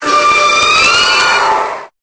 Cri de Momartik dans Pokémon Épée et Bouclier.